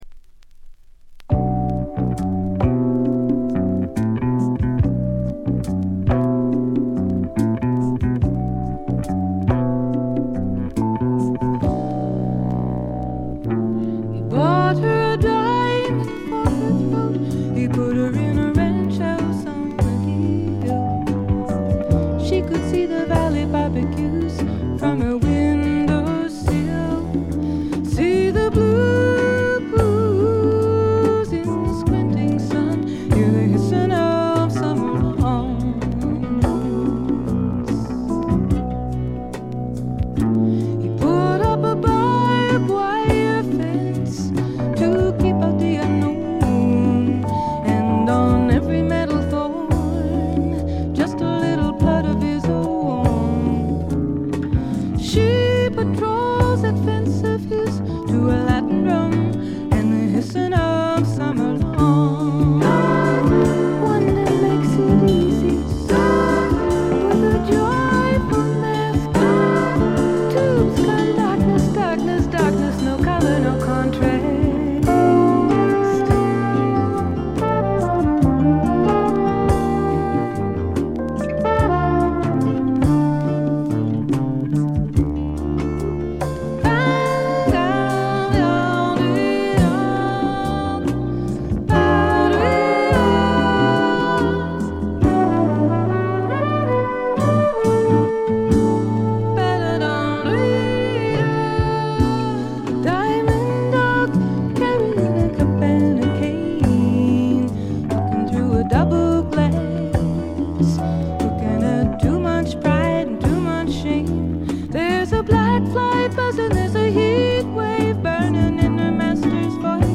微細なバックグラウンドノイズ程度でほとんどノイズ感無し。
ここからが本格的なジャズ／フュージョン路線ということでフォーキーぽさは完全になくなりました。
試聴曲は現品からの取り込み音源です。